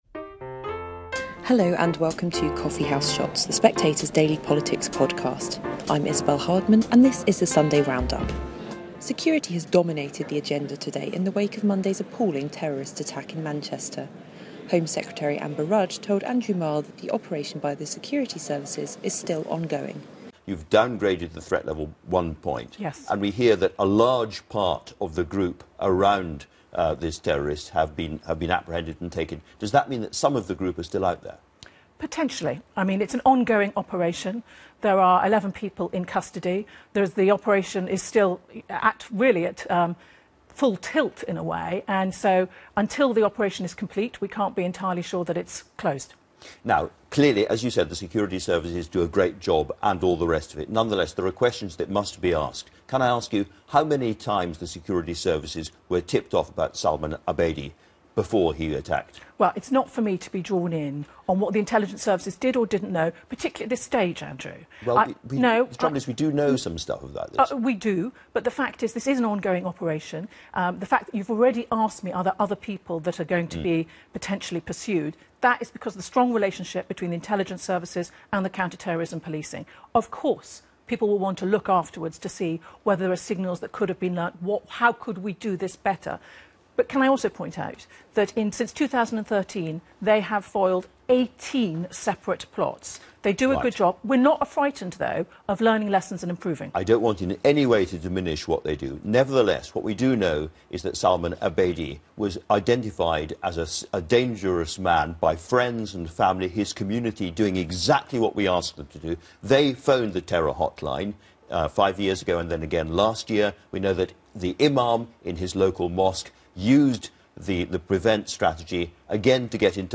Sunday Round Up 28/05/17 Coffee House Shots The Spectator News, Politics, Government, Daily News 4.4 • 2.1K Ratings 🗓 28 May 2017 ⏱ ? minutes 🔗 Recording | iTunes | RSS Summary The best of Sunday's political interviews all in one place.